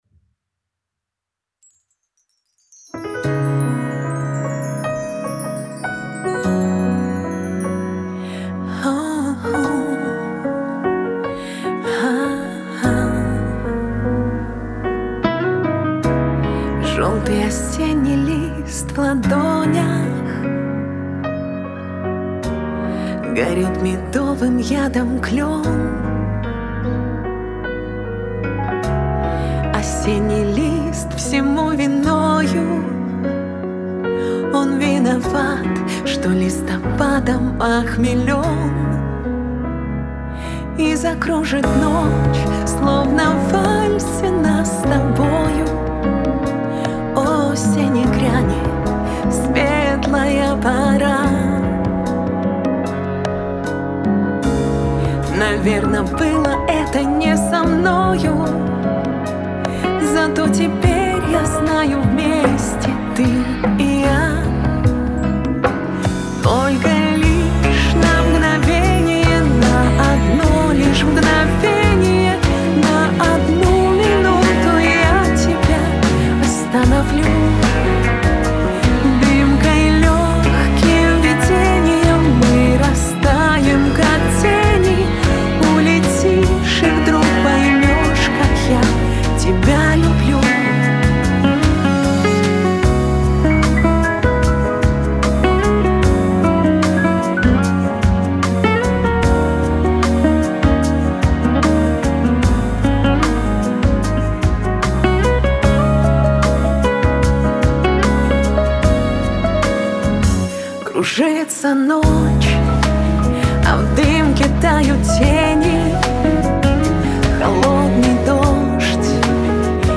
Заменил малый,добавил акуст.бочку,немного поработал с вокалом,чуть изменил кое-где баланс (прибрал гитары,перкуссию) Что скажете?